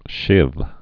(shĭv) Slang